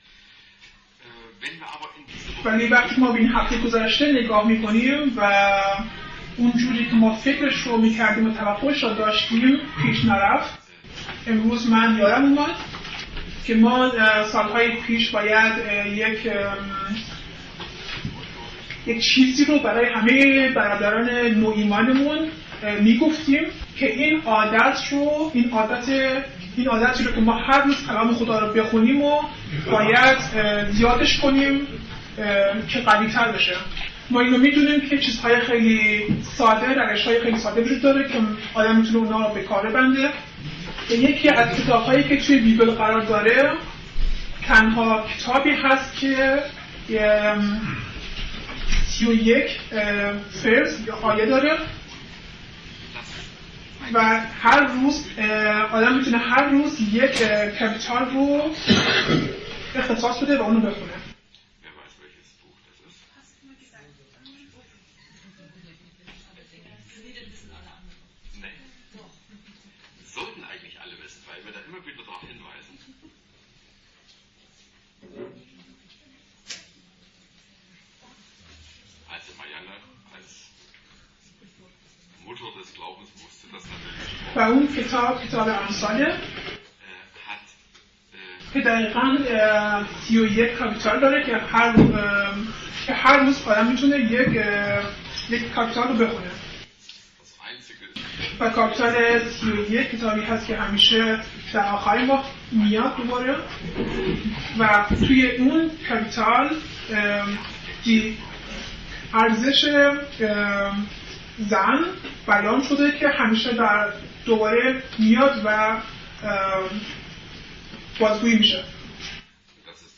Übersetzung in Farsi